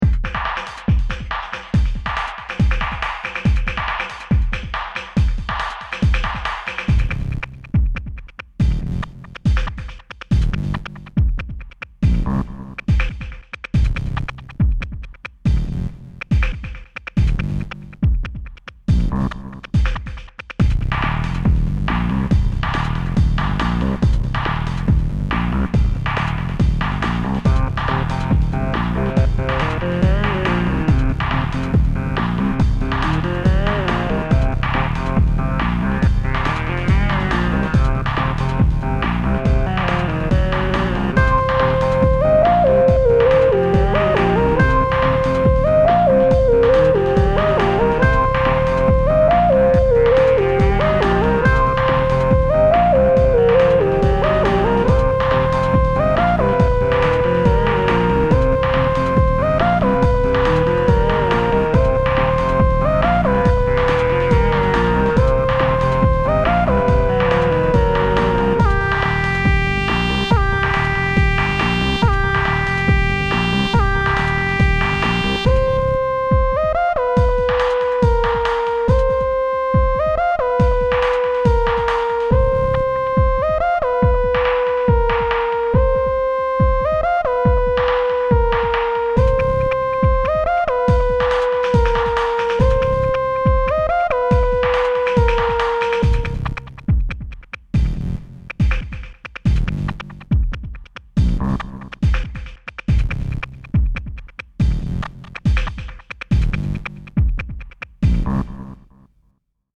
Hard house